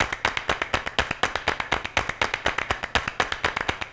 ClapYoHandz.wav